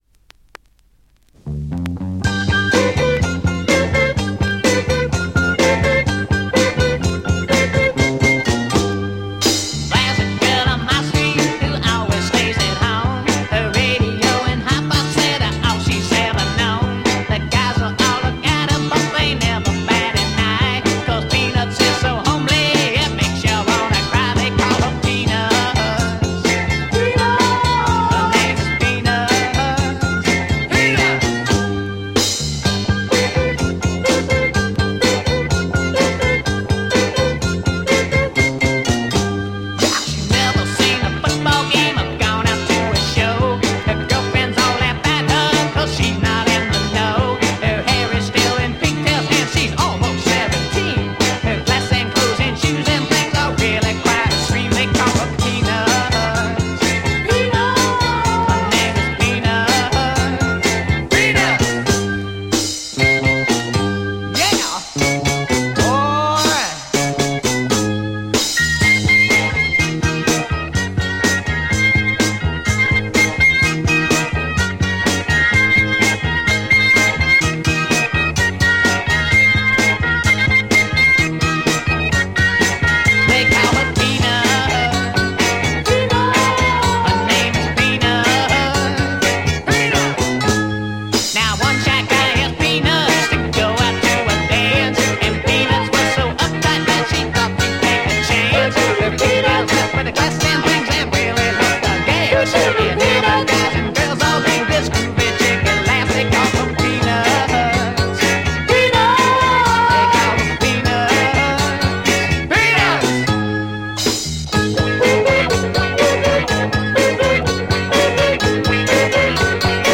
Great Druggy Psych dancer